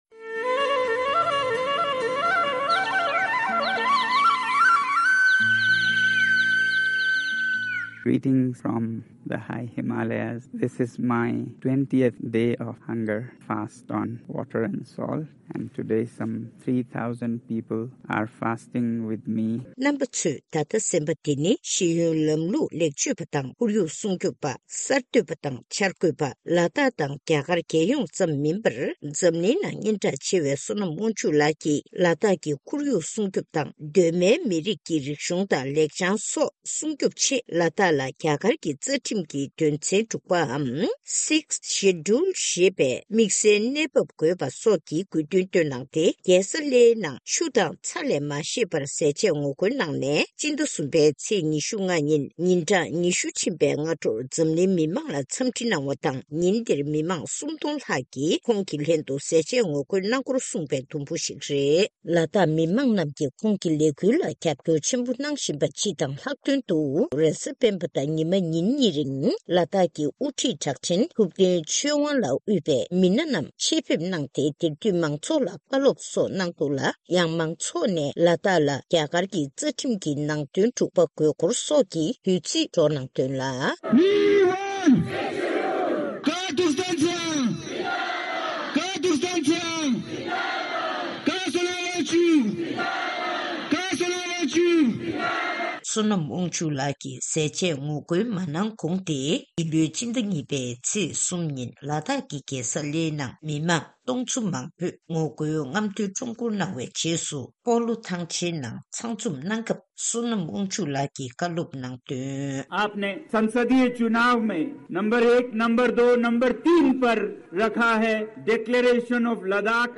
ཕྱོགས་བསྡུས་ཞུས་པའི་གནས་ཚུལ།